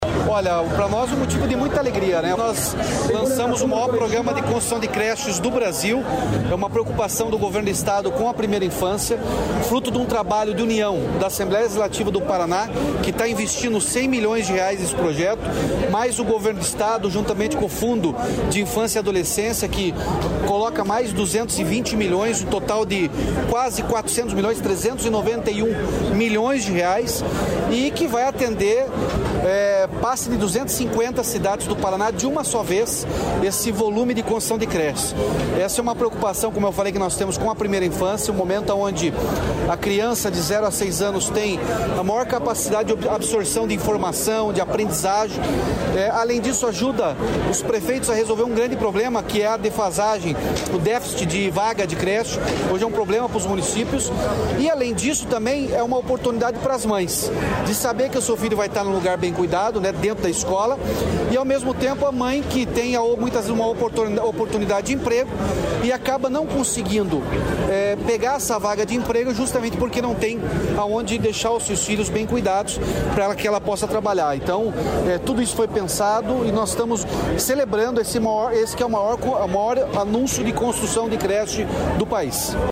Sonora do governador Ratinho Junior sobre a construção de 300 creches